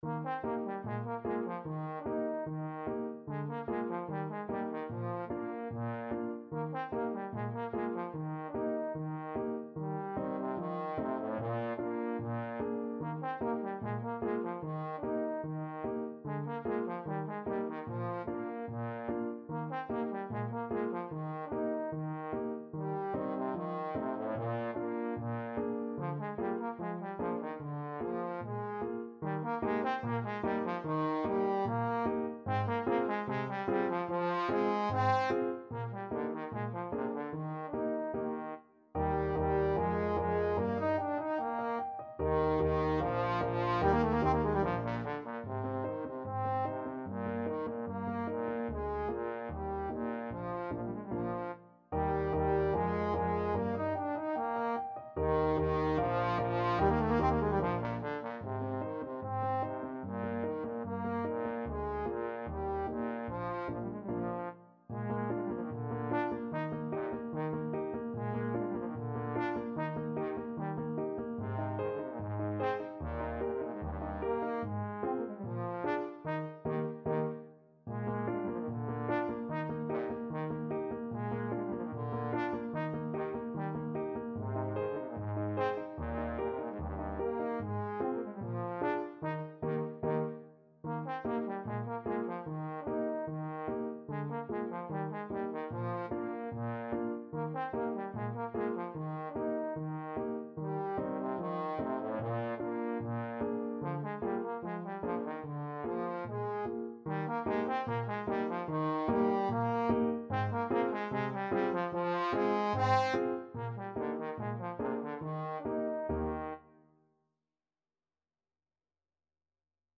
Play (or use space bar on your keyboard) Pause Music Playalong - Piano Accompaniment Playalong Band Accompaniment not yet available transpose reset tempo print settings full screen
Trombone
2/2 (View more 2/2 Music)
G3-Eb5
Eb major (Sounding Pitch) (View more Eb major Music for Trombone )
Allegretto = 74
Classical (View more Classical Trombone Music)